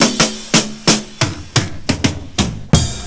FILL 6    -L.wav